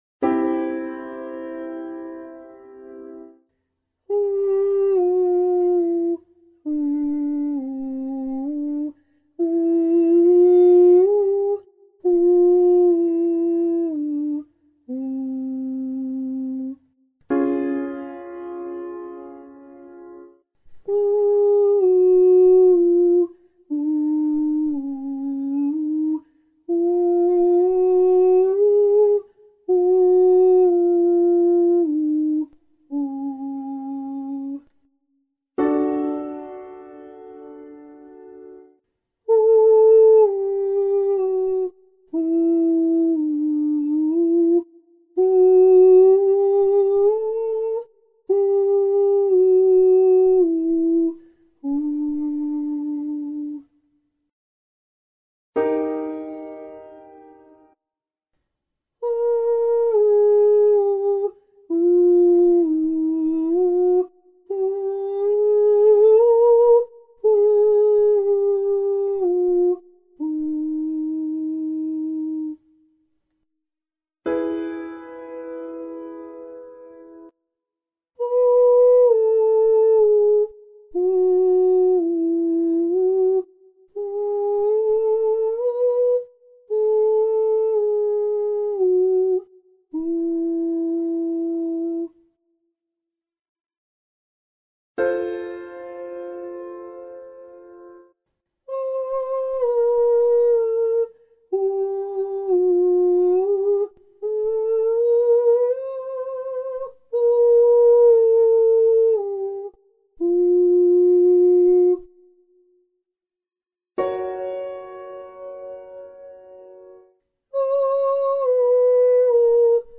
46A Breathe Every Three on /whoo/